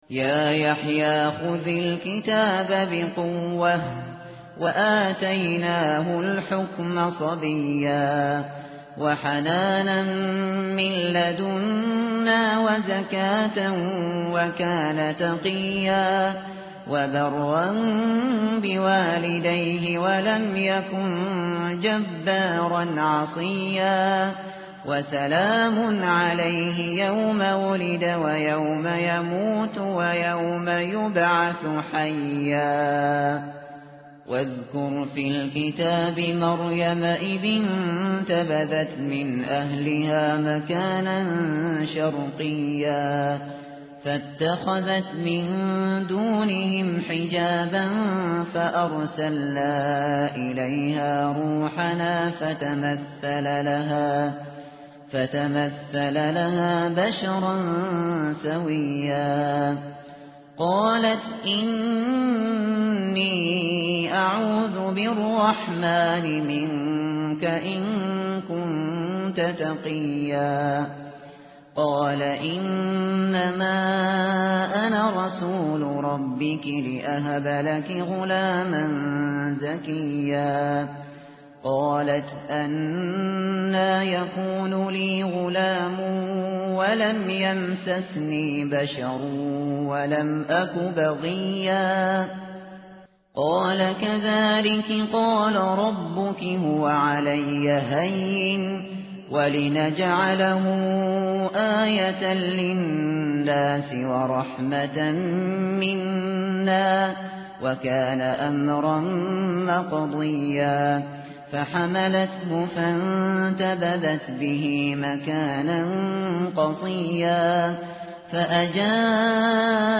متن قرآن همراه باتلاوت قرآن و ترجمه
tartil_shateri_page_306.mp3